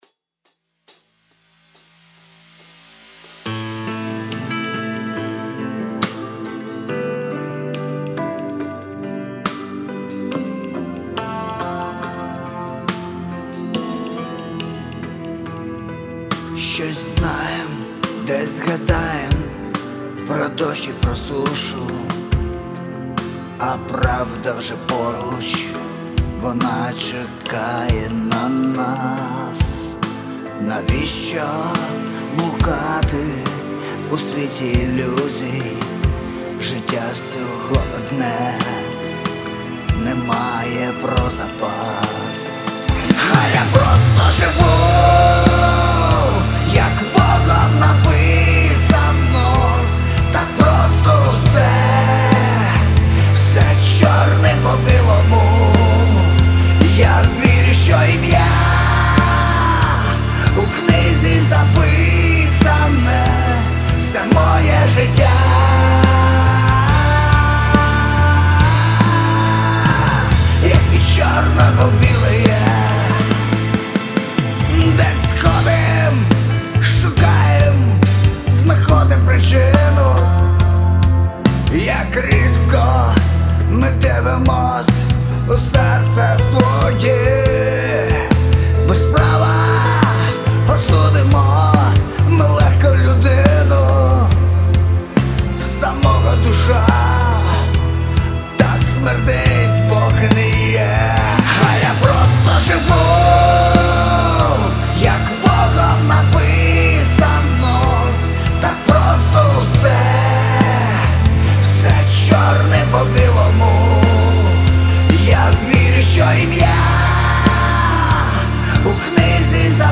66 просмотров 147 прослушиваний 5 скачиваний BPM: 70